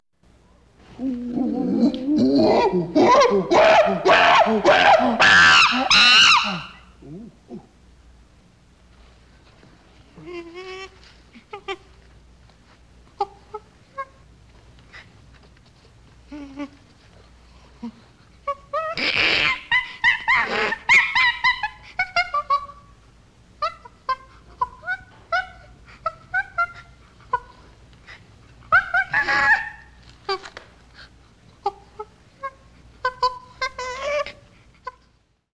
the-sound-of-screaming-chimps